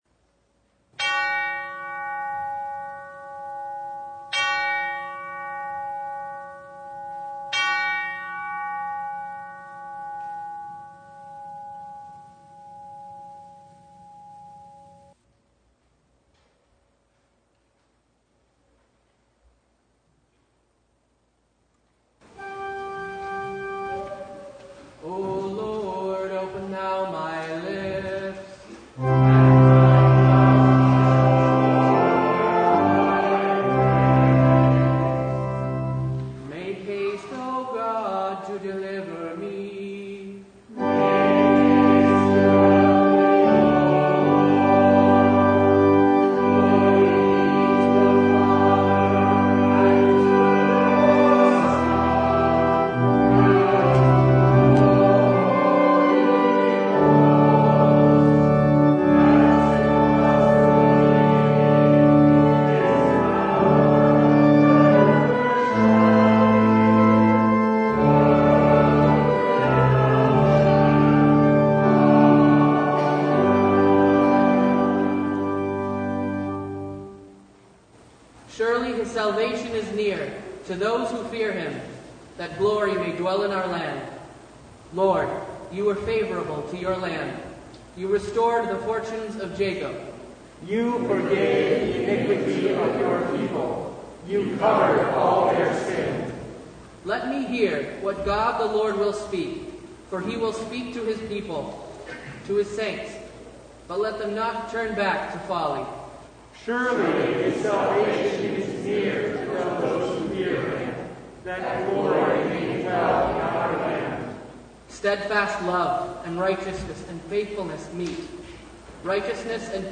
The Lord is not slow to fulfill His promise, but Advent slows us down as we learn to wait on the Lord who is patient with us. Due to a recording problem, the final hymn was copied from last weeks Advent Vespers service.
Service Type: Advent Vespers